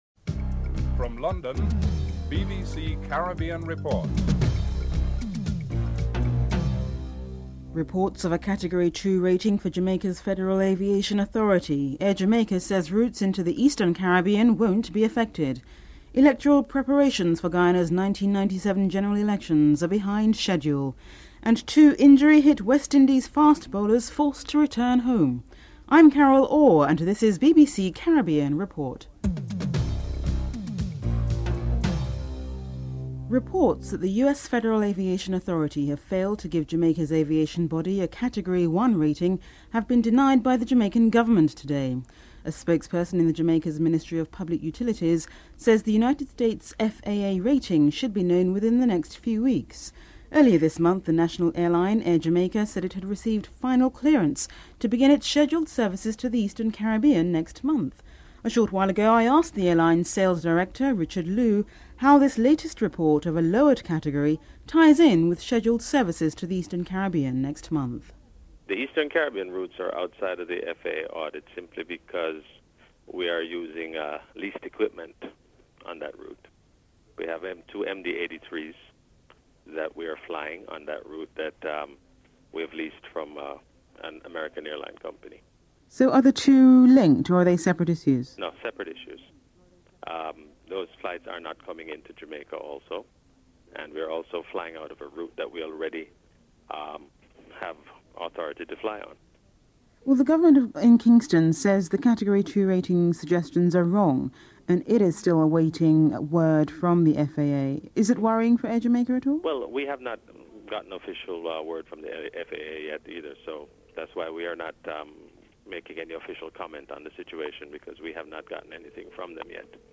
1. Headlines (00:00-00:28)
Labour MP, Hugh Bayley is interviewed.